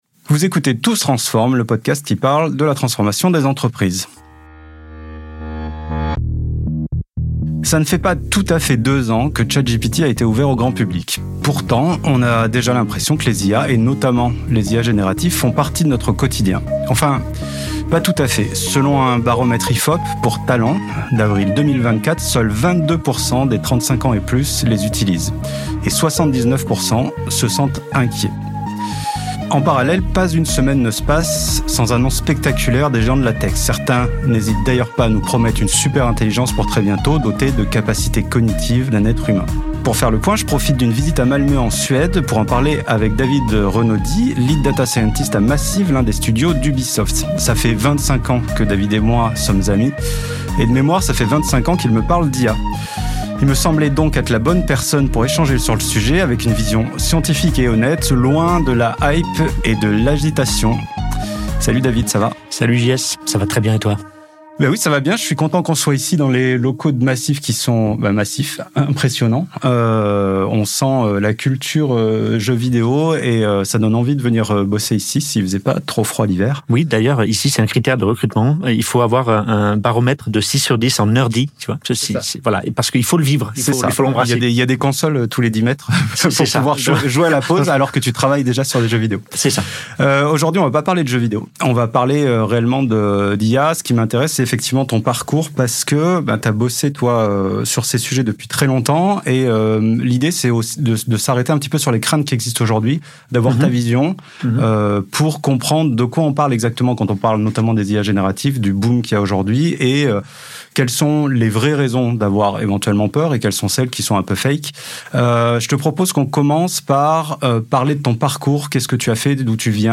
Et en plus on se marre !